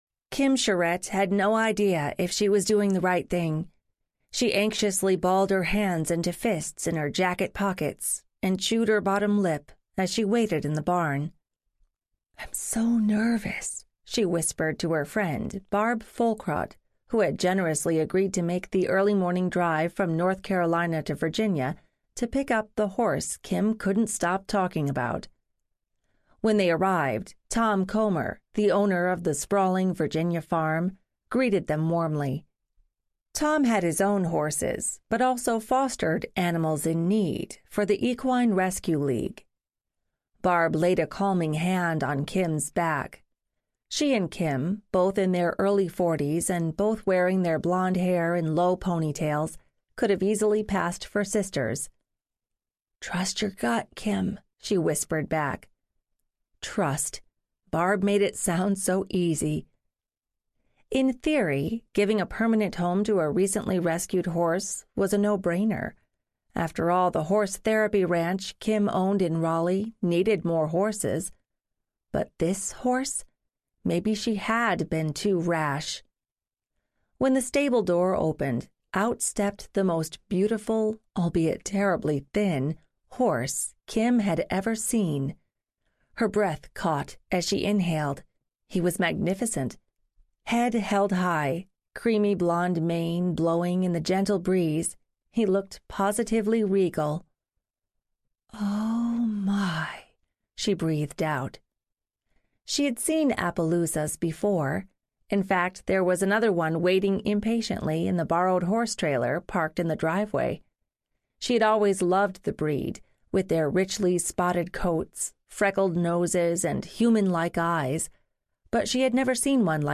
Joey Audiobook
Narrator
7.4 Hrs. – Unabridged